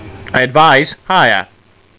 Amiga 8-bit Sampled Voice
higher.mp3